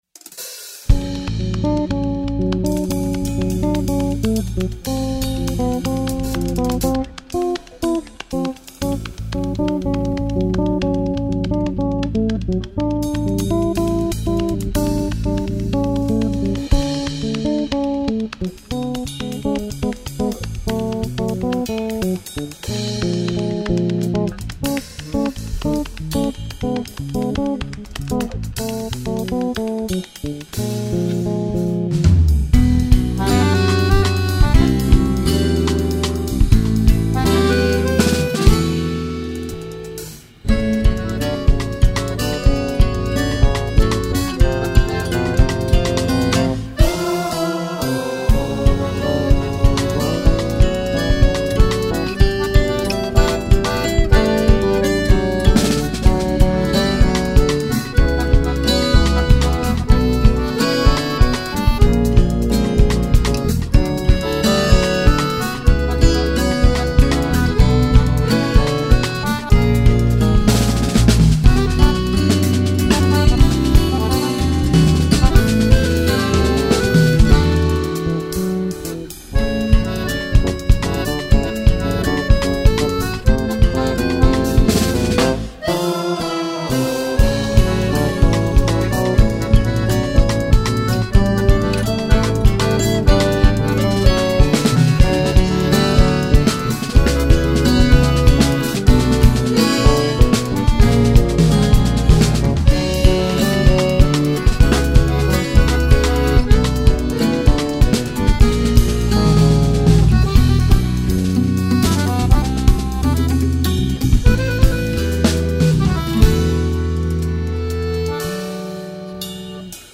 2810   05:00:00   Faixa: 8    Jazz
Bateria, Percussão
Baixo Elétrico 6, Violao Acústico 6
Acoordeon